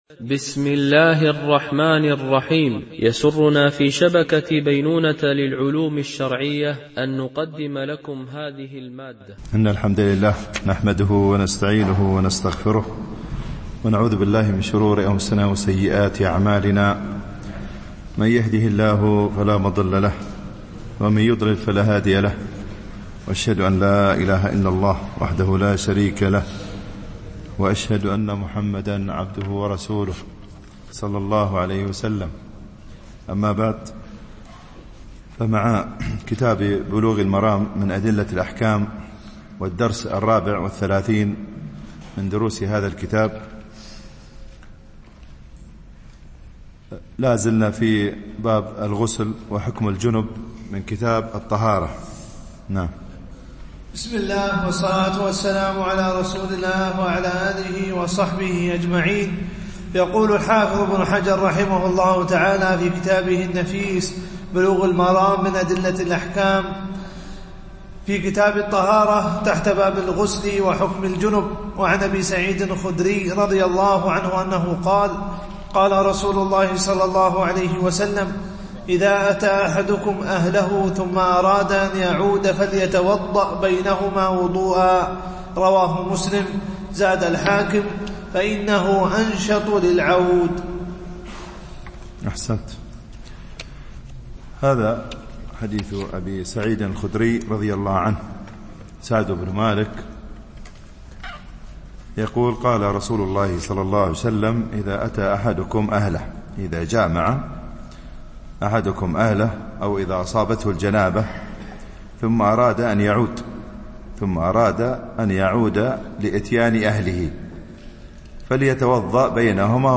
شرح بلوغ المرام من أدلة الأحكام - الدرس 34 ( كتاب الطهارة - باب الغسل وحكم الجنب، الحديث 111 - 115)